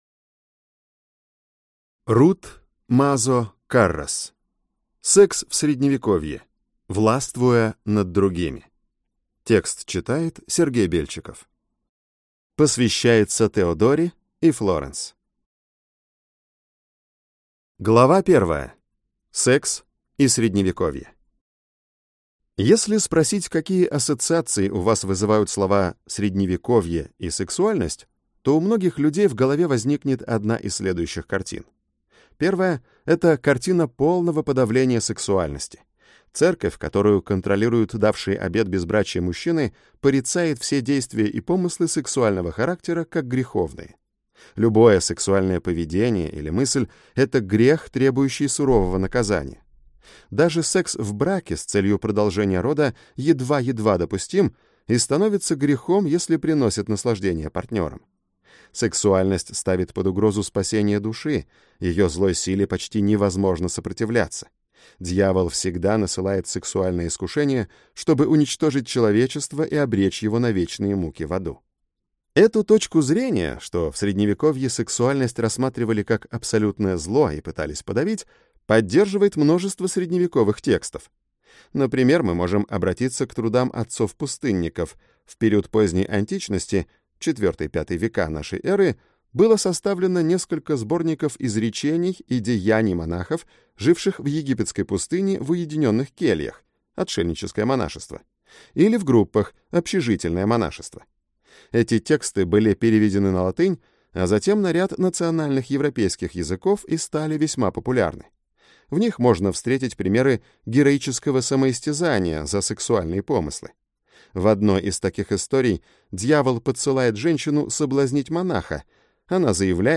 Аудиокнига Секс в Средневековье | Библиотека аудиокниг